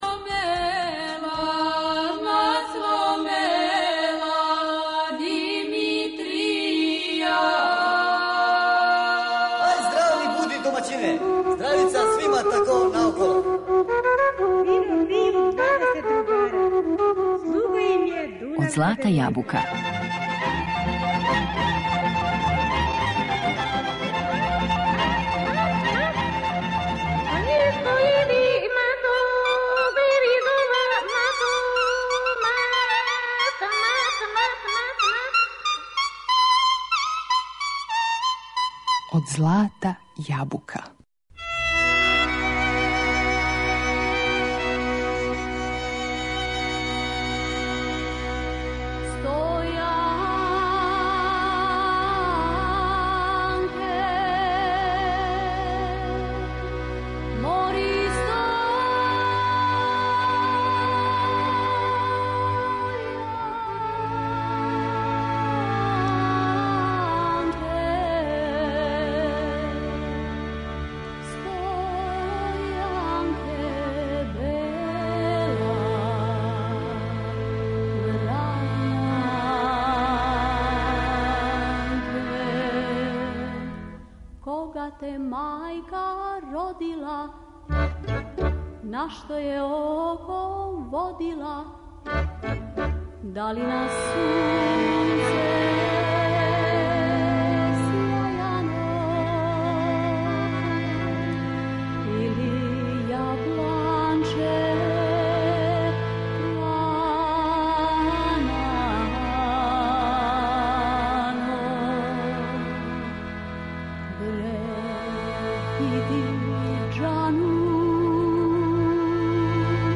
Музички портрет Василије Радојчић (2. део)
Представићемо музички портрет уметнице Василије Радојчић, чији значај је од непроцењиве вредности за интерпретацију српске традиционалне музике.
Певачку каријеру почела је на Радио Београду 1958.године. Репертоар јој је био веома богат, али мелодије из јужне Србије, обојене њеним аутентичним извођењем, сврстале су је у најпознатијег извођача врањанског мелоса.